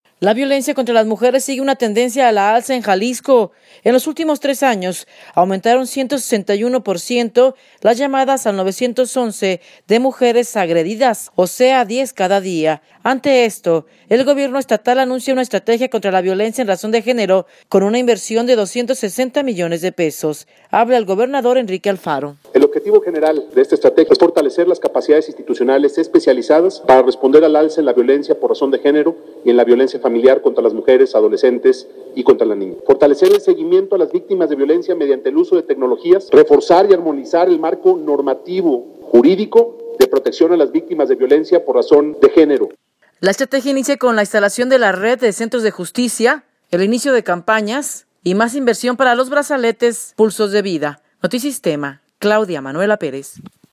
Ante esto, el Gobierno Estatal anuncia una estrategia contra la violencia en razón de género con una inversión de 260 millones de pesos. Habla el gobernador, Enrique Alfaro.